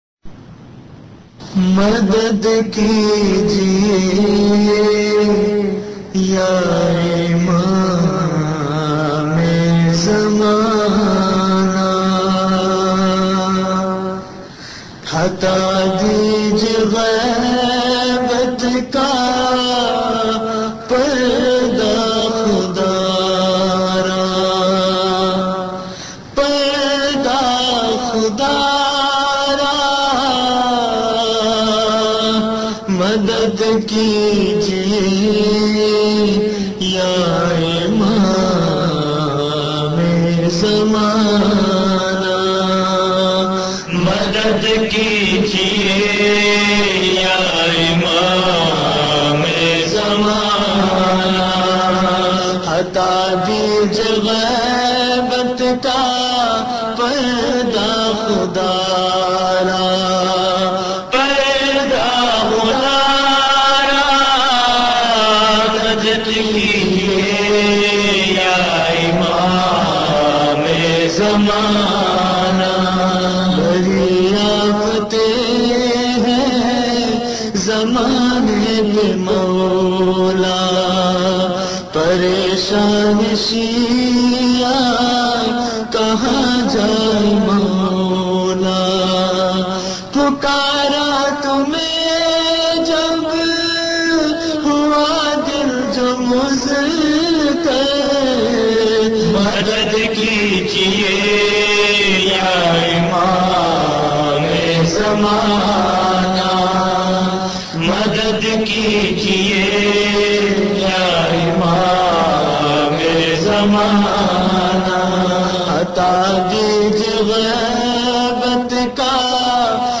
Non Saff Classic Munajaats